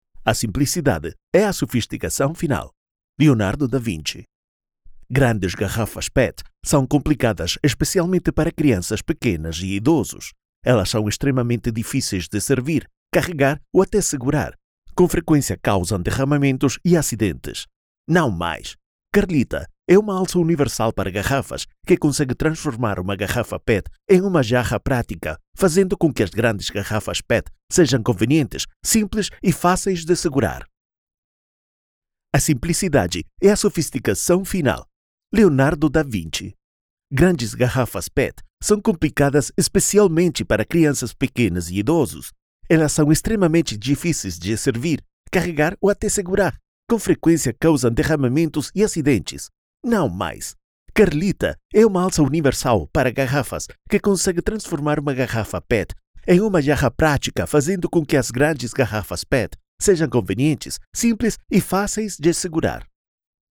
Commercial, Distinctive, Accessible, Versatile, Reliable
Commercial
His voice is often described as fresh, young, calm, warm, and friendly — the “guy next door” — ideal for commercials, audiobooks, narration, promos, YouTube and educational content, e-learning, presentations, and podcasts.
As a full-time producer, studio owner, and musician, he ensures clean, echo-free, uncompressed audio, delivered in any format.